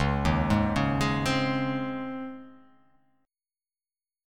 DbM7sus2 chord